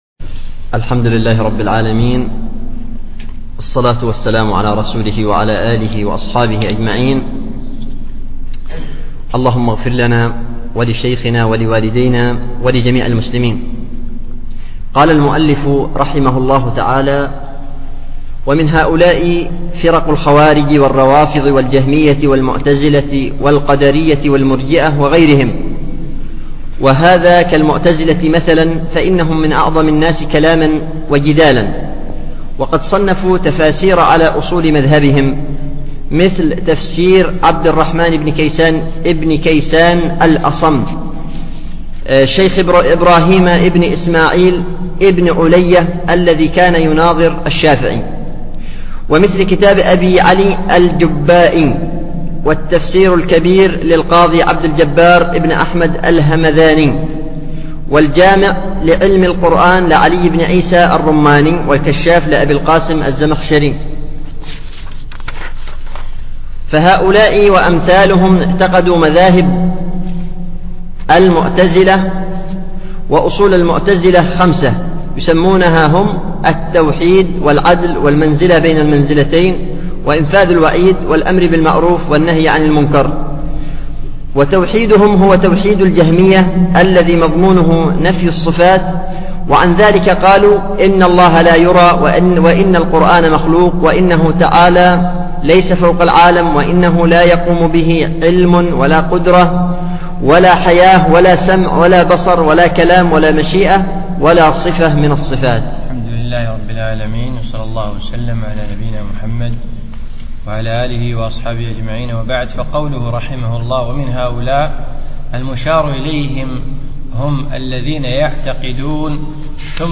الشرح في شهر 9 عام 2011 في دورة الخليفة الراشد الخامسة